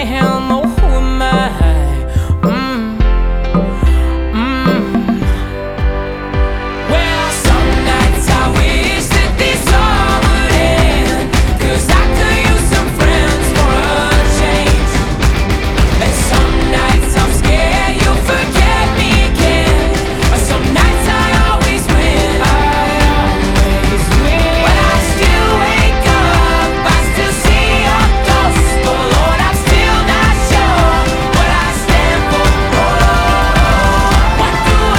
2012-02-21 Жанр: Альтернатива Длительность